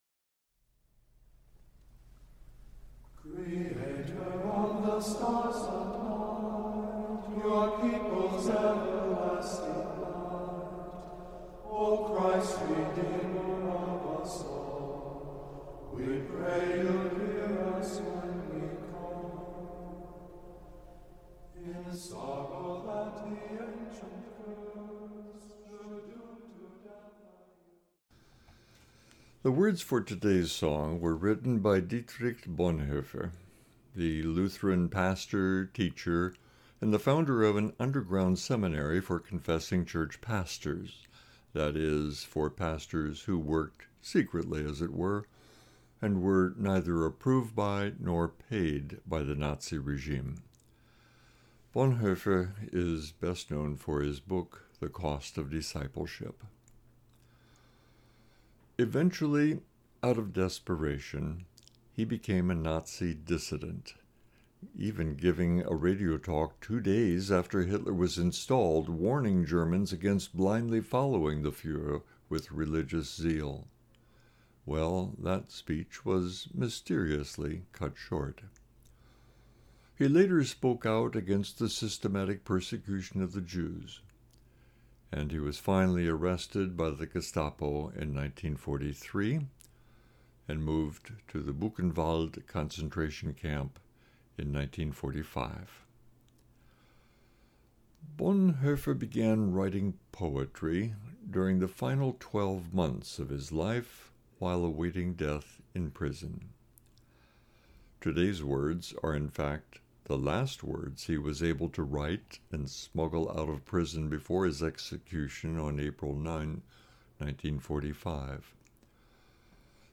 2023-01-01 Meditation for the Second Sunday of Christmas and New Years Day (Bonhoeffer, Von guten amächten wunderbar geborgen)